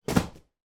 Звук упавшего мягкого предмета в игровом центре